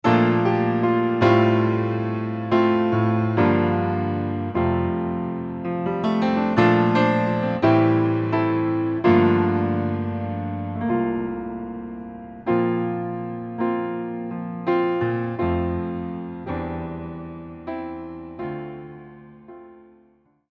Here are some examples of our Yamaha Disklavier piano sound.